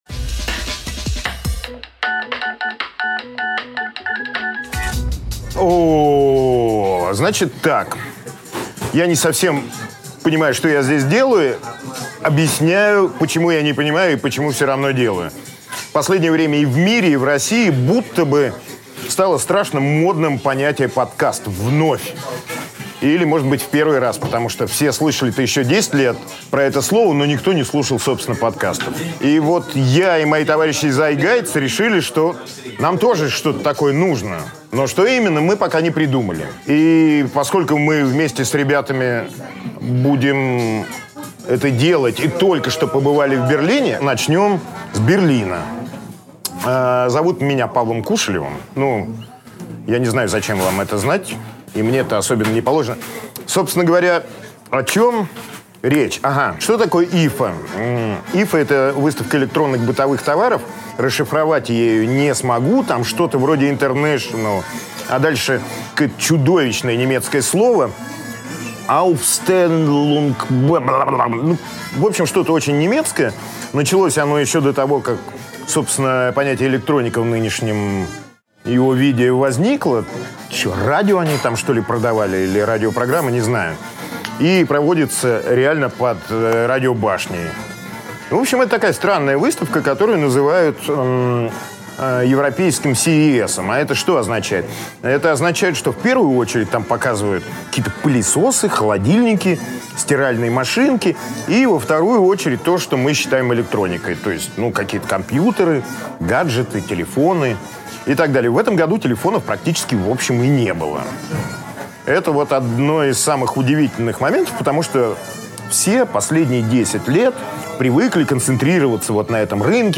Аудиокнига IFA, Берлин, Транспорт 2.0 | Библиотека аудиокниг
Aудиокнига IFA, Берлин, Транспорт 2.0 Автор Павел Кушелев.